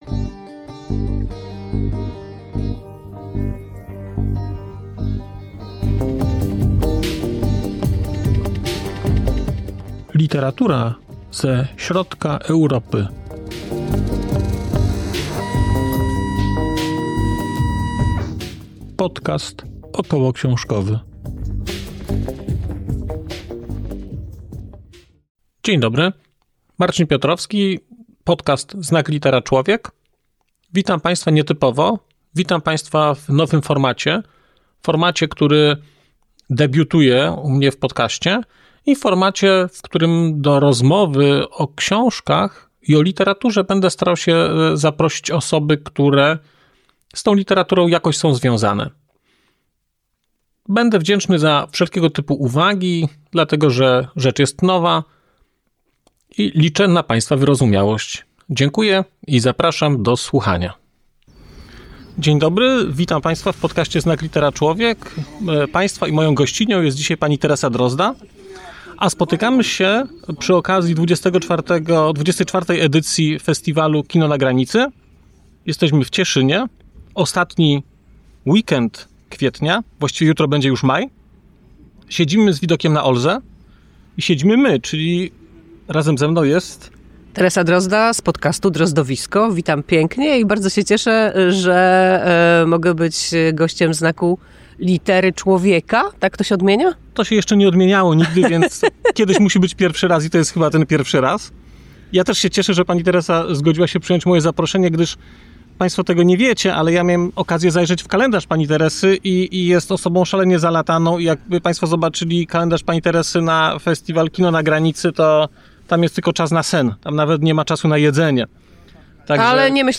A spotkaliśmy się, w Cieszynie przy okazji festiwalu Kino na Granicy.